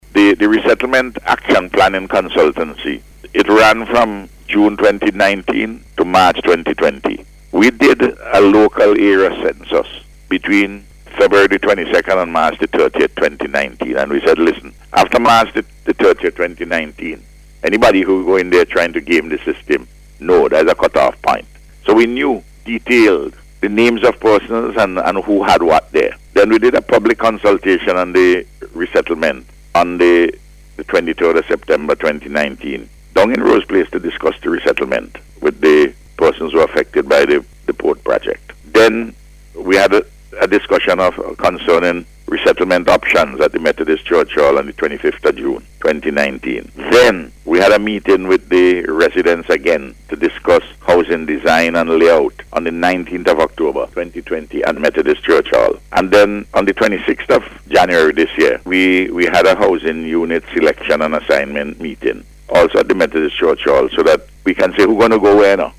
Speaking on NBC s Face to Face programme, the Prime Minister said 210 persons benefitted from direct and indirect employment, as a result of the construction of the houses.